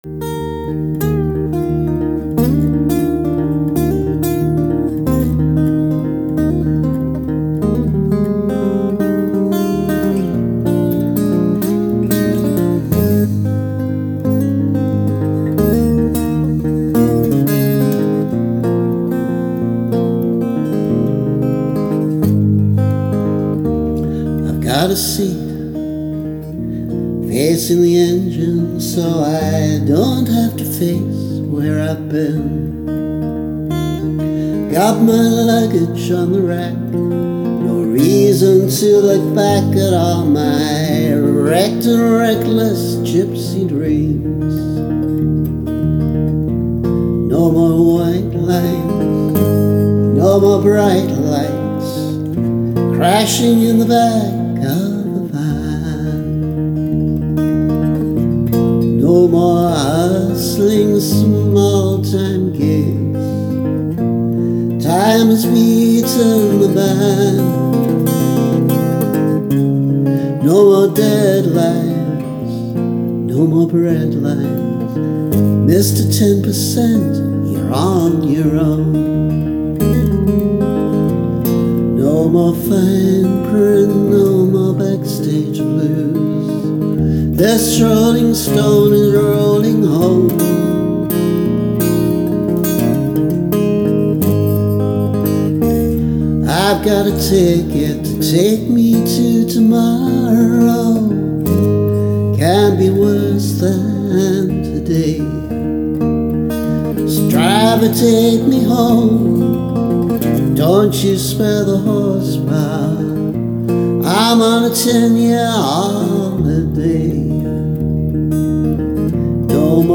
Recorded in the 80s using slide guitar: this version, though, has no slide. Dropped D tuning.